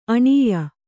♪ aṇīya